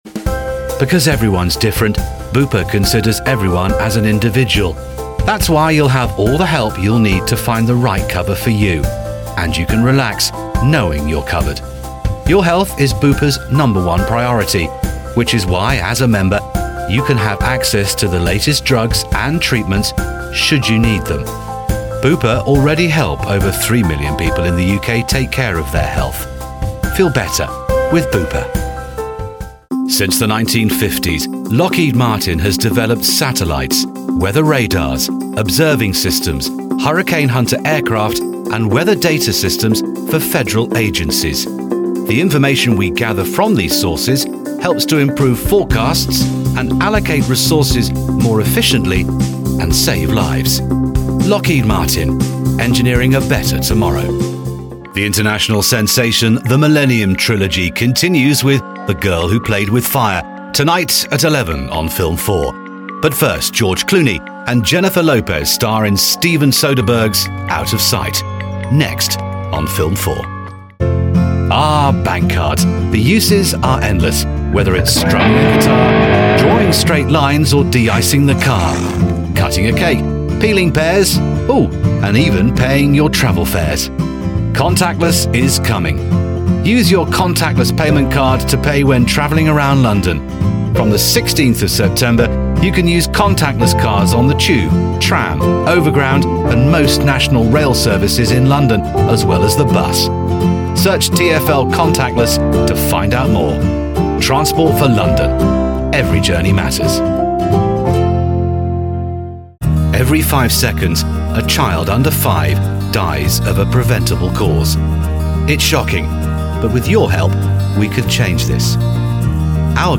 英-外籍108BBC男